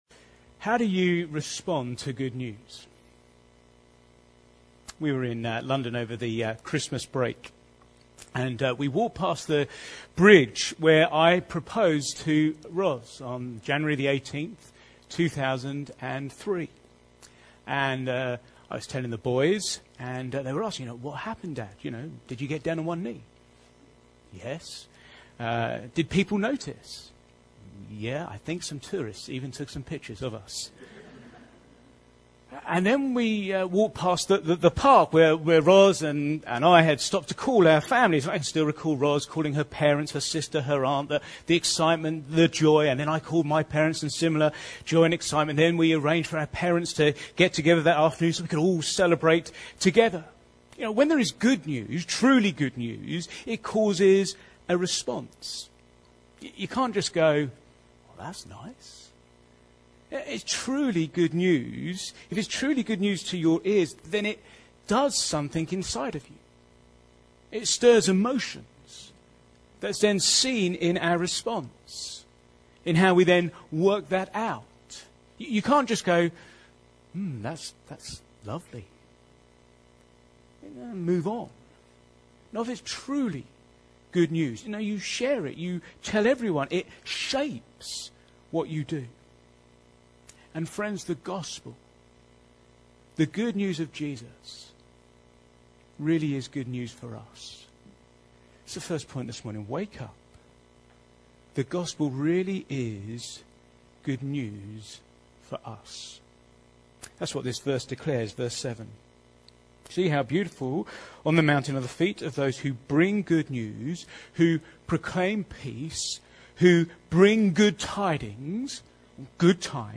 Back to Sermons Beautiful Feet